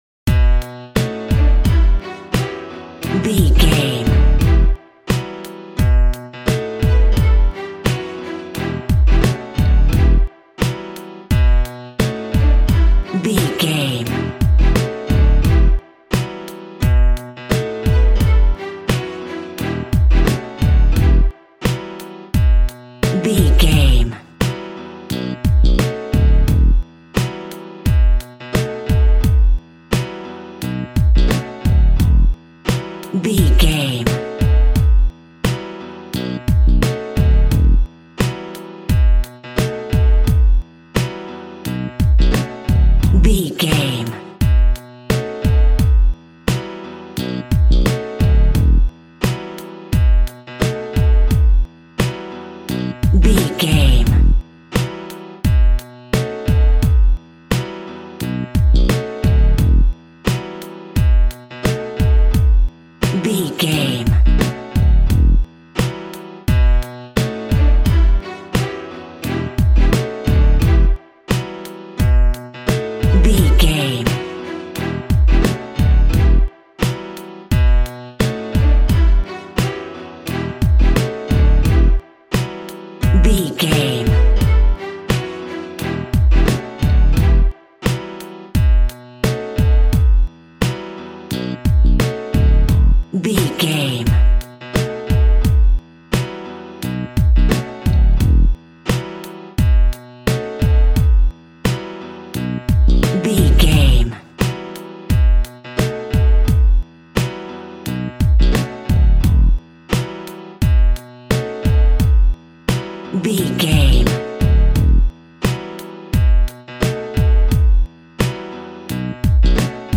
Aeolian/Minor
DOES THIS CLIP CONTAINS LYRICS OR HUMAN VOICE?
WHAT’S THE TEMPO OF THE CLIP?
Slow
hip hop music
drum machine
synths